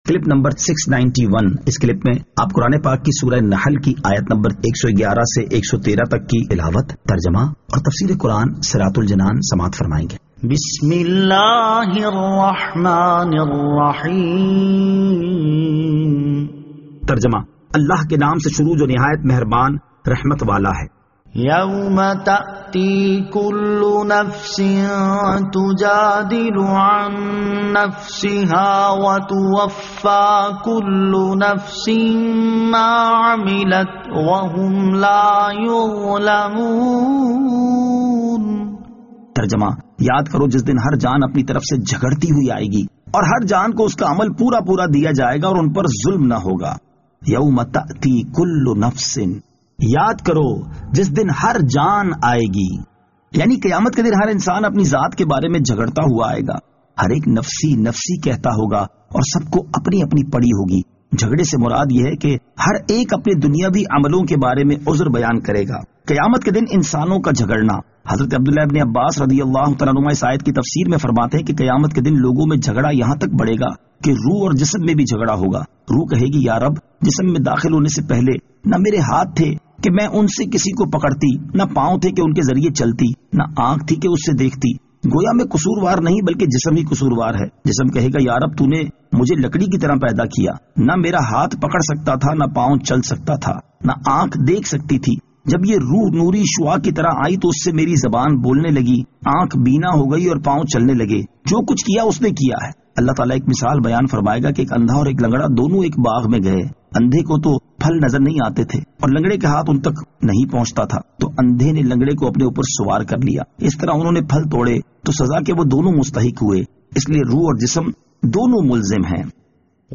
Surah An-Nahl Ayat 111 To 113 Tilawat , Tarjama , Tafseer